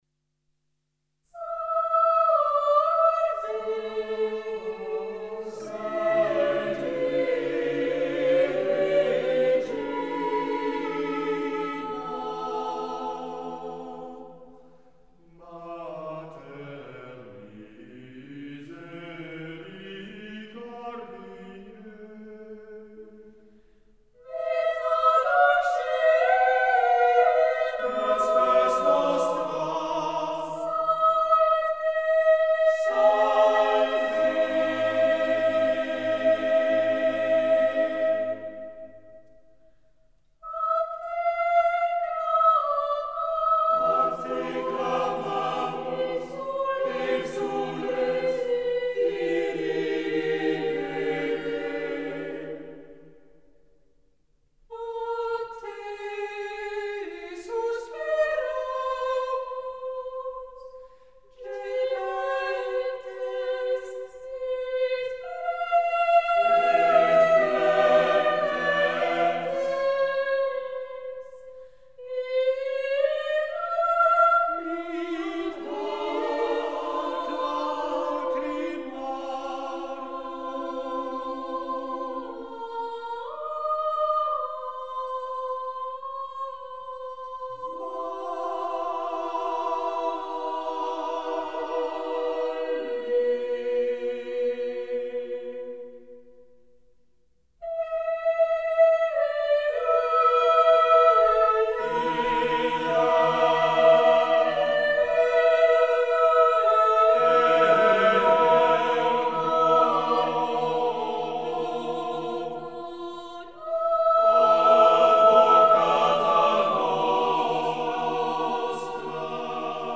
Composition for four mixed voices SATB a cappella choir
🎶 an example of performance : 🎧